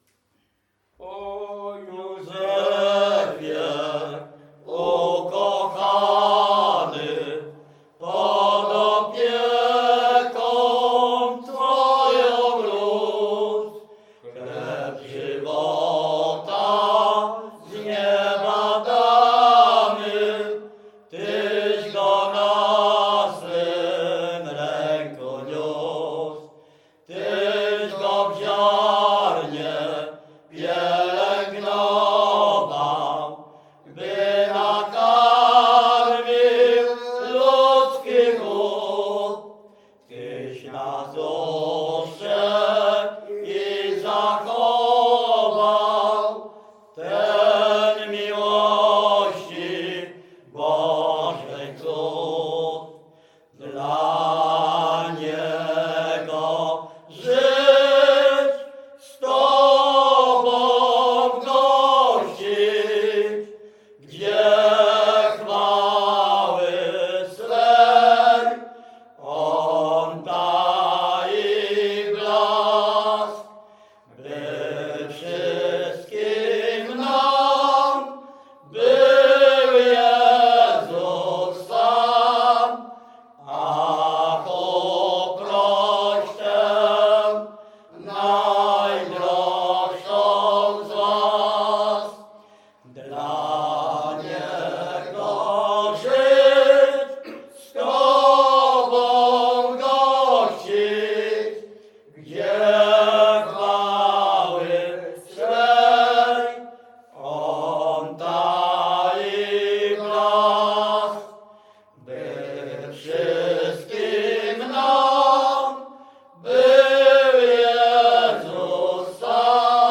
Śpiewacy z Ruszkowa Pierwszego
Wielkopolska, powiat kolski, gmina Kościelec, wieś Ruszków Pierwszy
mają bardzo bogaty repertuar pieśni (zwłaszcza religijnych) w miejscowych wariantach melodycznych
Pogrzebowa
pogrzebowe nabożne katolickie do grobu o świętych